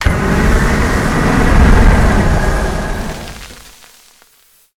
metahunt/flamethrower_shot_05.wav at dfc221d77e348ec7e63a960bbac48111fd5b6b76
flamethrower_shot_05.wav